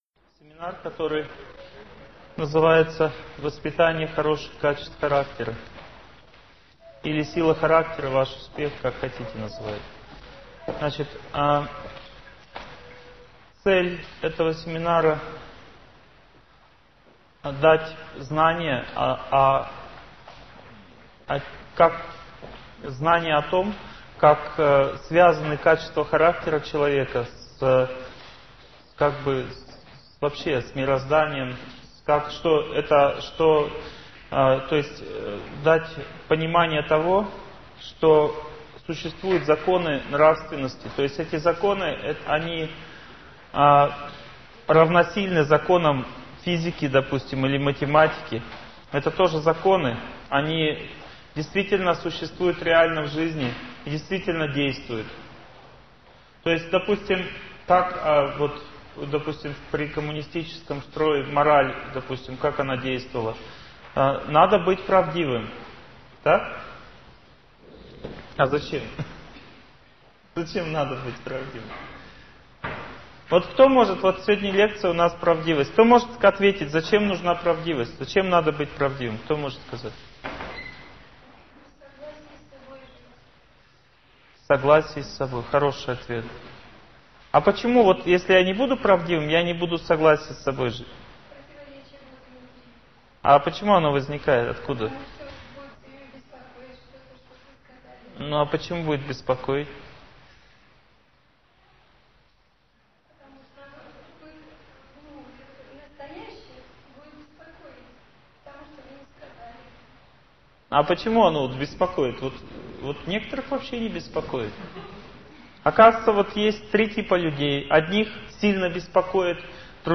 Аудиокнига Воспитание хороших качеств характера | Библиотека аудиокниг